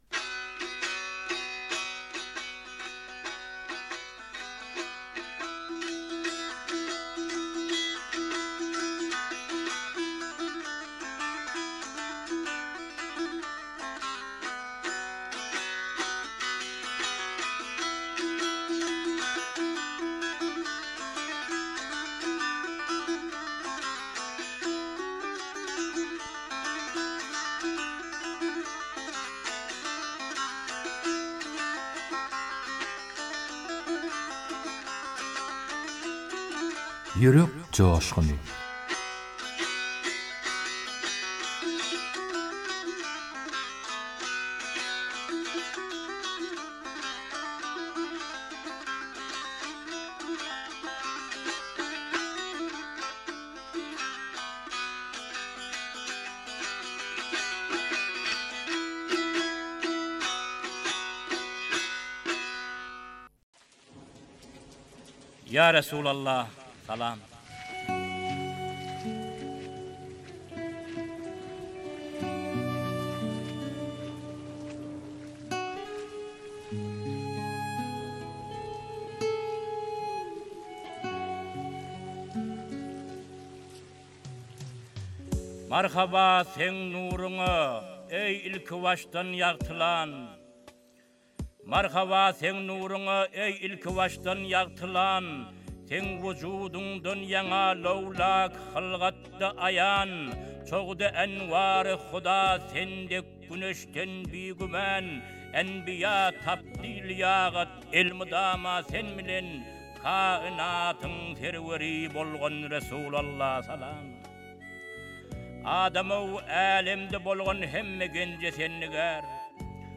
turkmen goşgy owaz aýdym şygyrlar